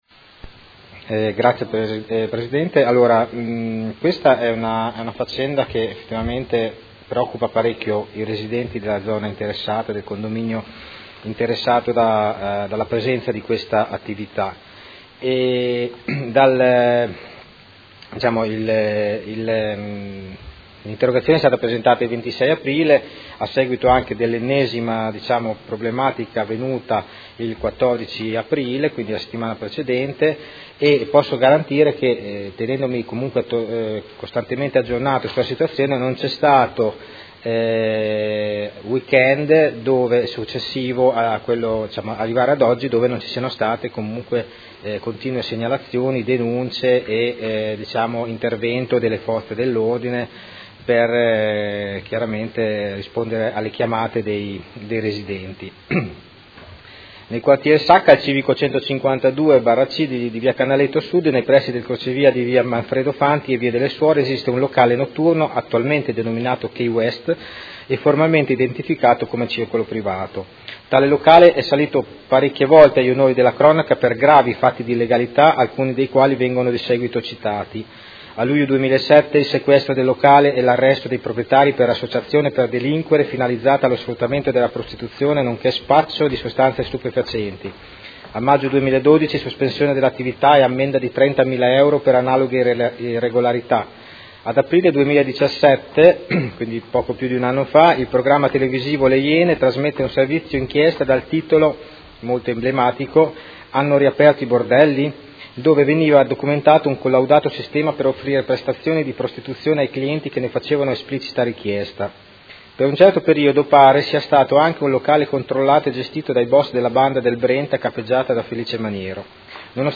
Seduta del 21/01/2018 Interrogazione del Consigliere Stella (Art1-MDP/Per Me Modena) avente per oggetto: Problematiche di convivenza con i residenti e forti probabilità di attività illegali di un locale notturno della zona Sacca